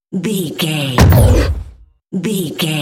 Dramatic monster hit
Sound Effects
heavy
intense
dark
aggressive
the trailer effect